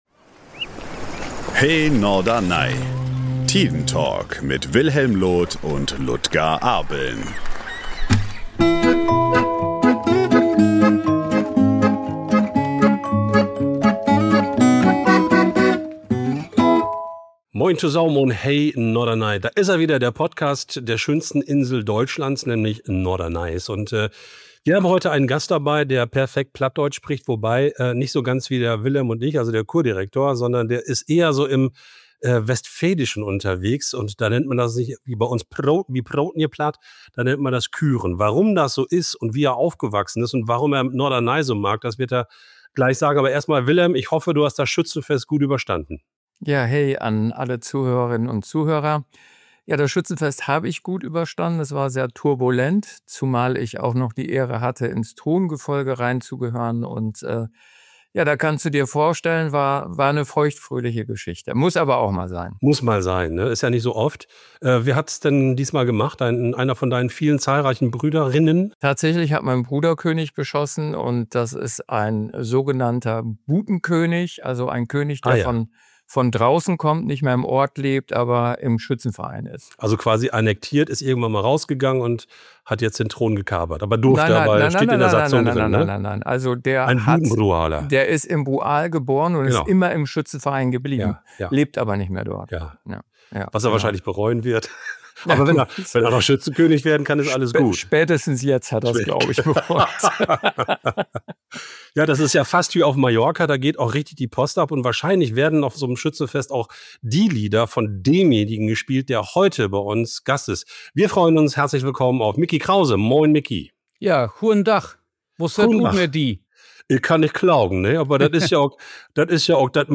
Ein Gespräch zwischen Strandkorb, Mofa-Erinnerungen und ganz viel echter Zuneigung zur Insel.